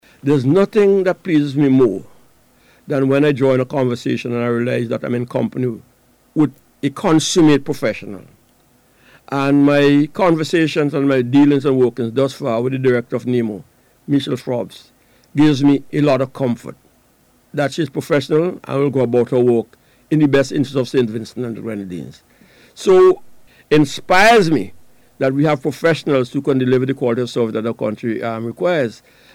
The commendation came from Deputy Prime Minister and Minister of with responsibility for Disaster Management, Major the Hon. St. Claire Leacock, while speaking on NBCs Face to Face program this morning.